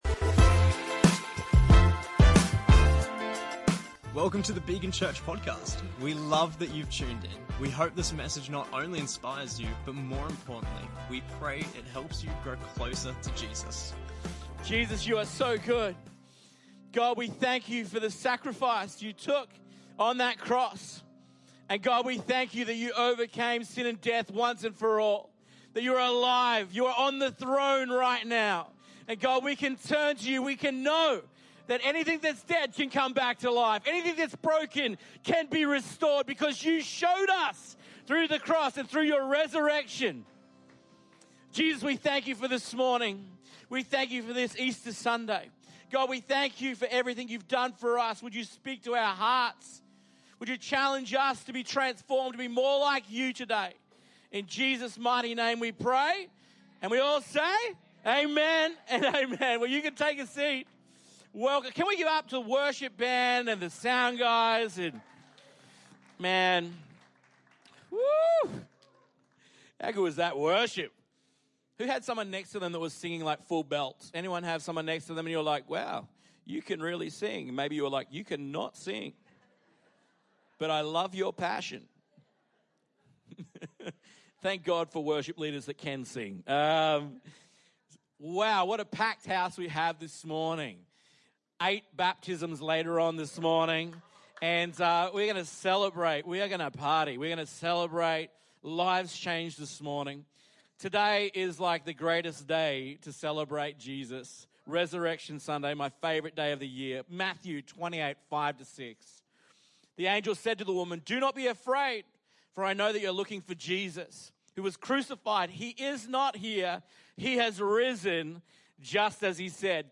Episode 107: Easter Sunday 2025